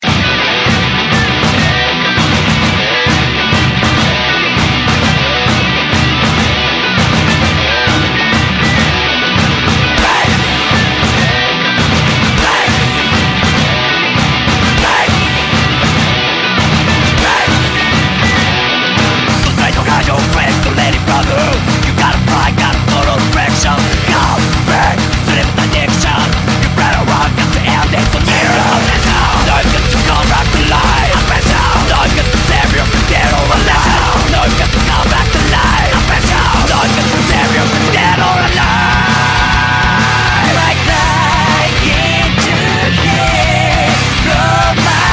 J-Pop